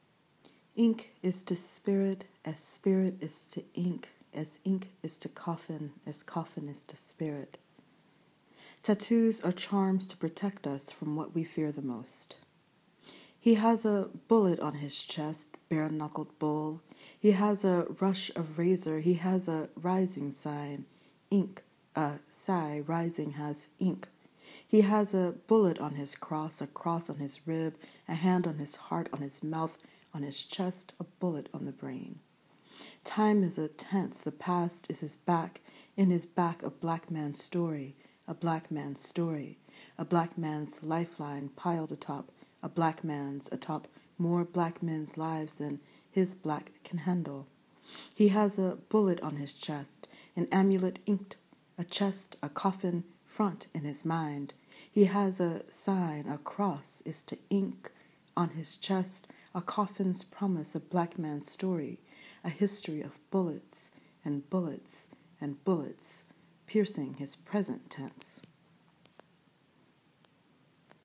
read her own work